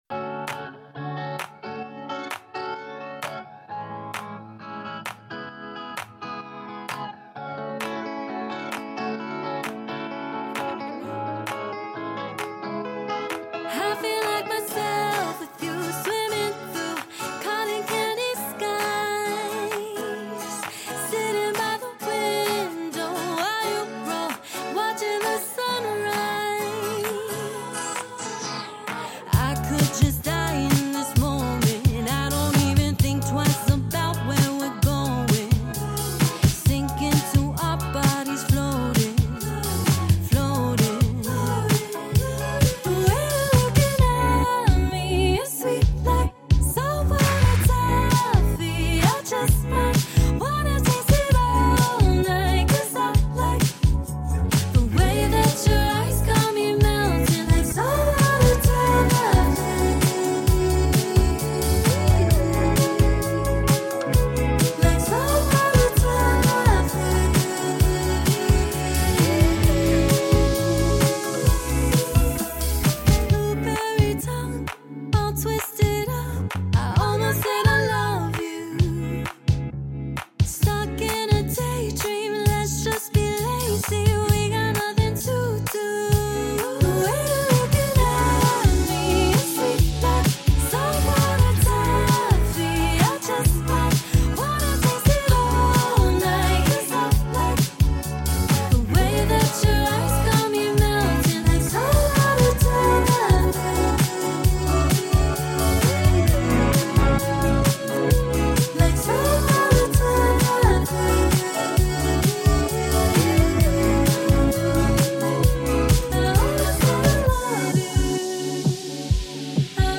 After last week’s devotion to the local scene, in this week’s podcast I chat to another local band – Leeds indie group The Calls.